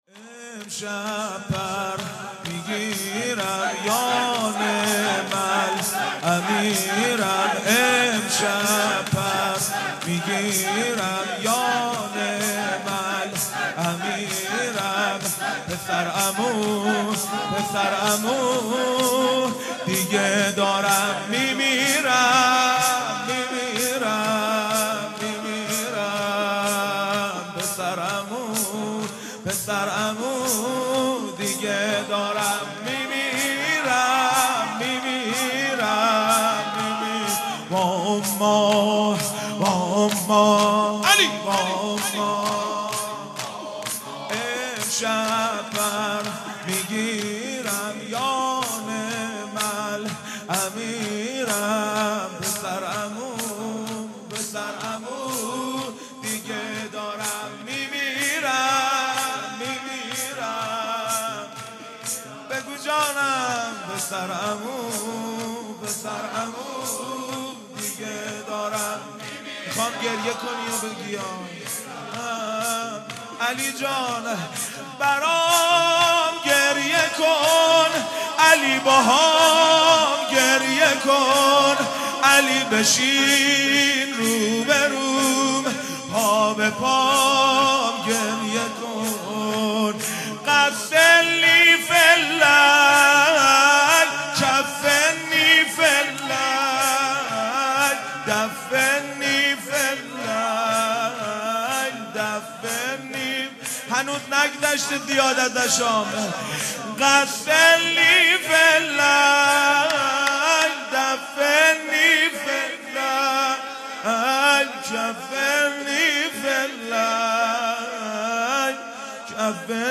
هیئت دانشجویی فاطمیون دانشگاه یزد
شور
شهادت حضرت زهرا (س) | ۲۵ بهمن ۱۳۹۵